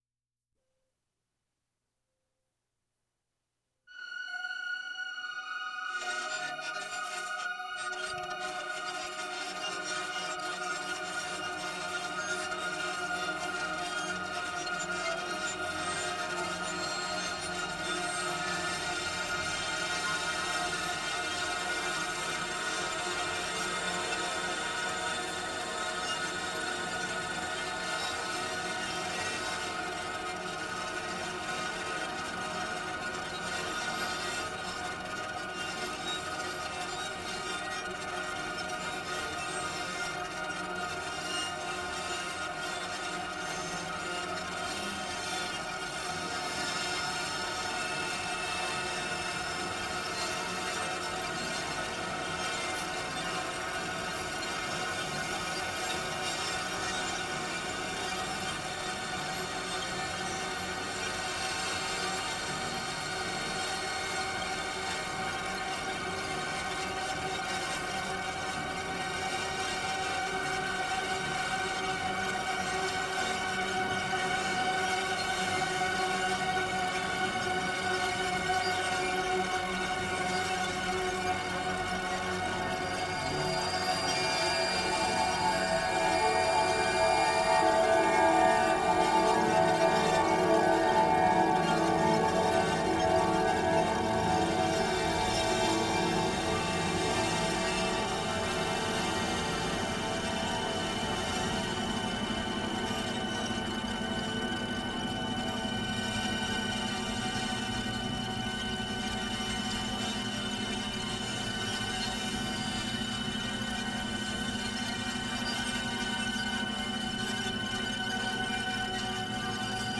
A selection of four home studio recordings dubbed together as one, produced with various software synthesizers and two reel-to-reel machines over the summer of 2020.